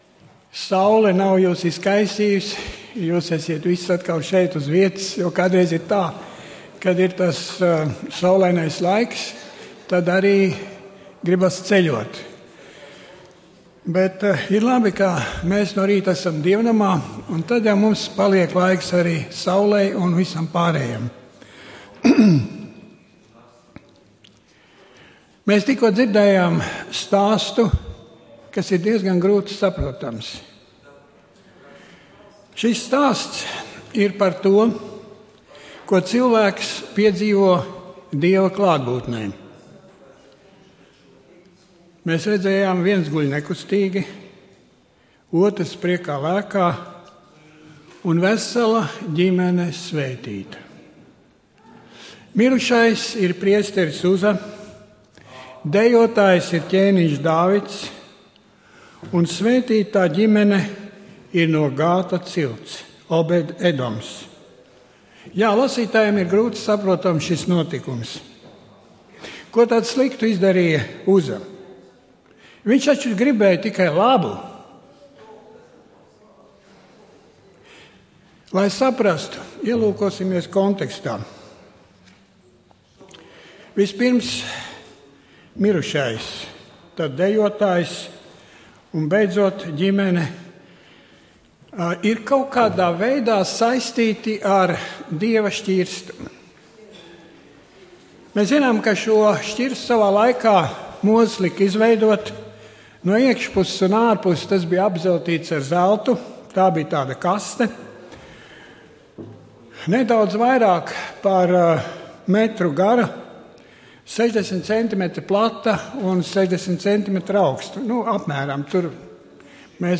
Dievkalpojums 19.09.2015.